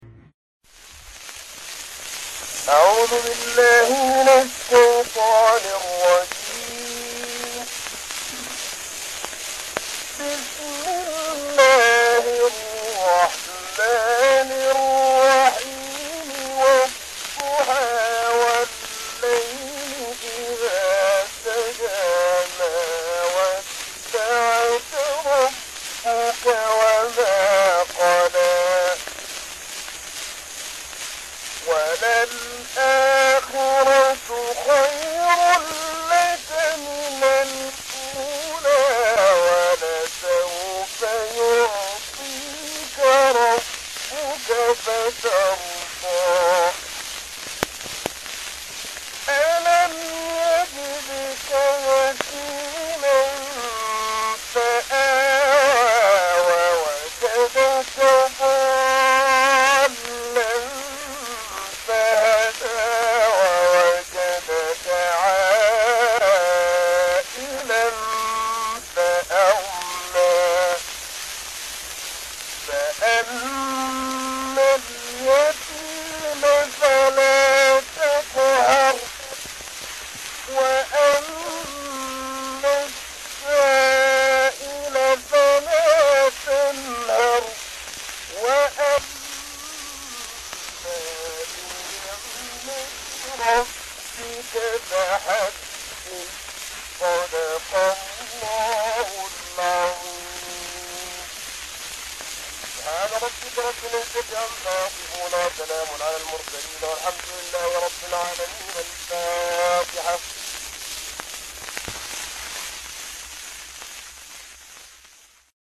İQNA - Qurani-Kərim qarisinin 131 il bundan əvvələ aid olan qədim səs yazısı tapılıb.
Alınan məlumatlara əsasən Müqəddəs Kəbədə ayələri oxuyan naməlum qarinin səsi fonoqrafla 1885-ci ildə yazılıb.
Ən qədim Quran tilavətinin səs yazısı